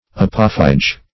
Apophyge \A*poph"y*ge\, n. [Gr.